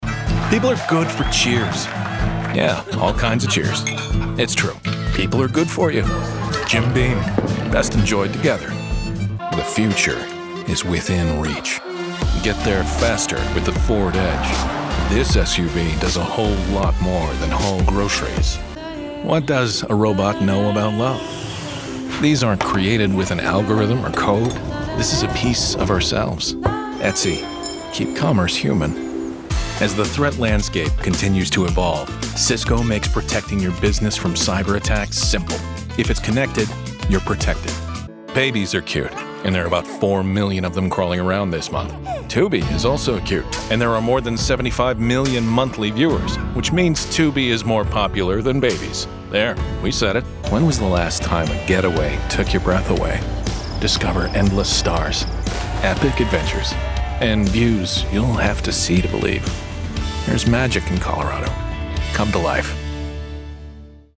Voiceover Artist
Commercial Demo Reel · Download